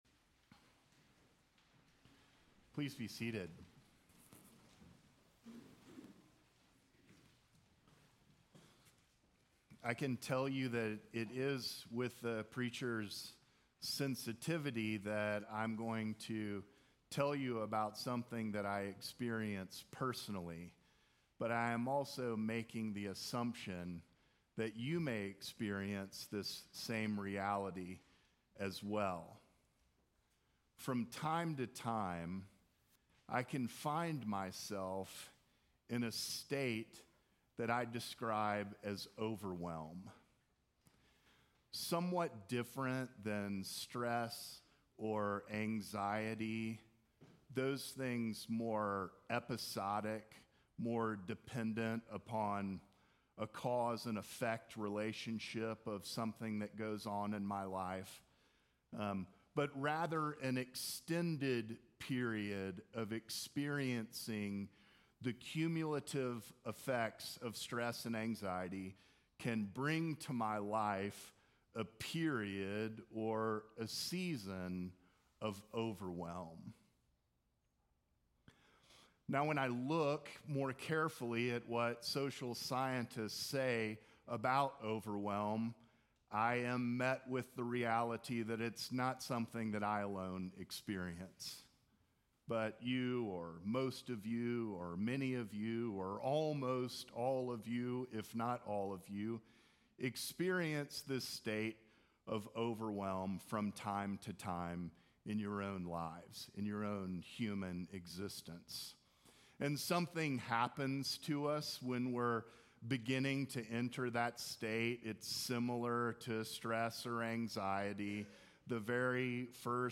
Sermons from St. John's Episcopal Church